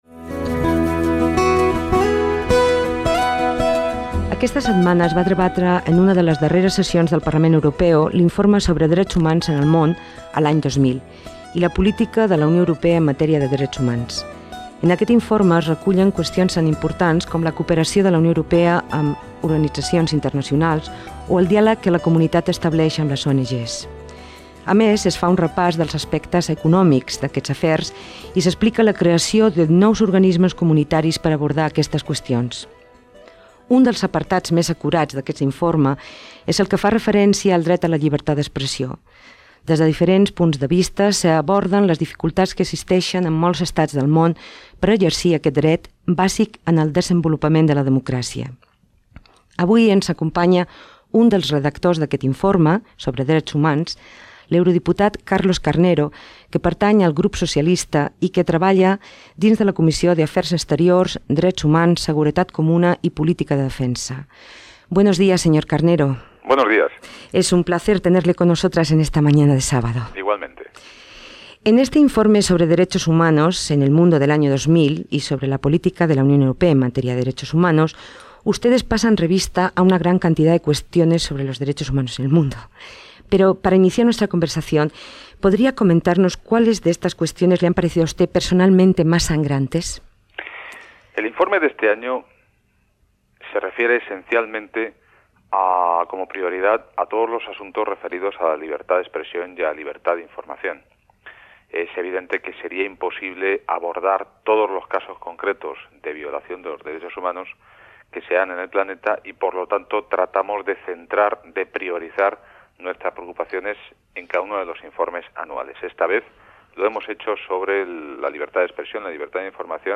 Entrevista a l'eurodiputal Carlos Carnero, redactor de l'informe sobre drets humans fet a l'any 2000
Informatiu